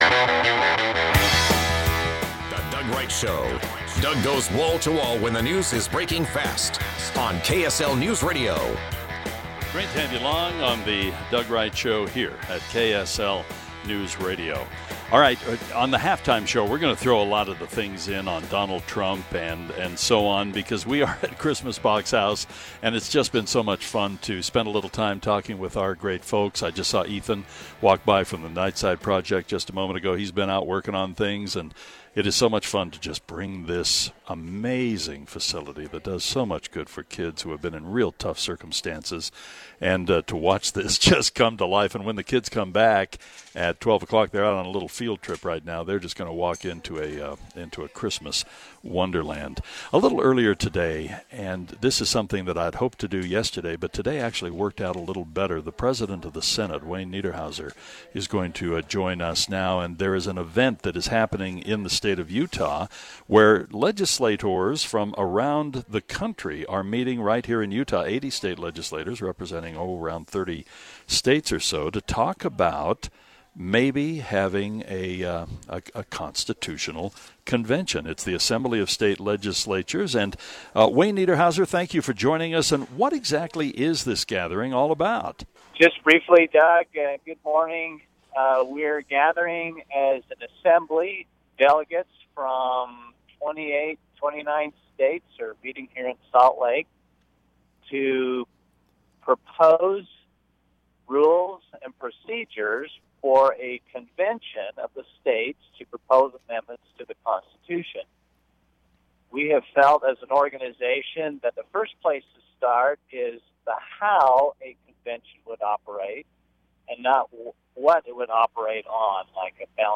Utah Senate President Wayne Niederhauser talks about why the Assembly of State Legislatures are meeting in Salt Lake City.